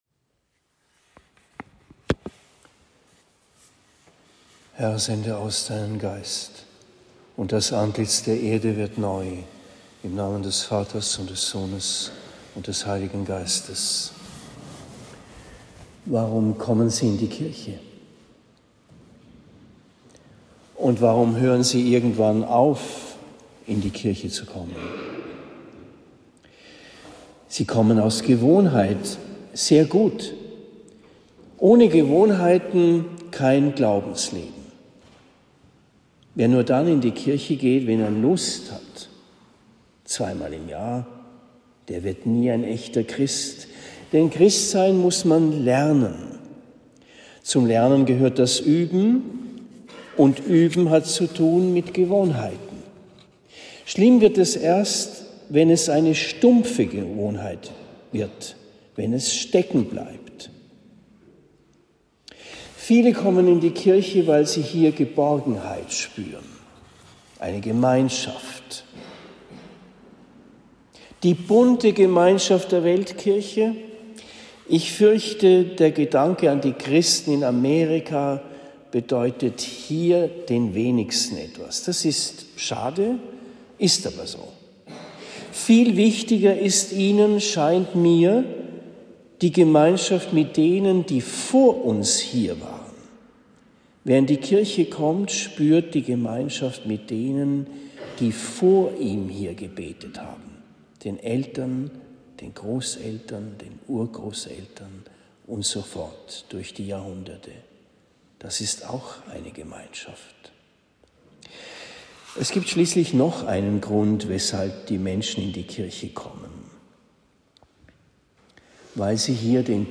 Predigt am 4. März 2023 in Oberndorf